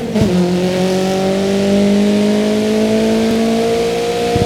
Index of /server/sound/vehicles/lwcars/renault_alpine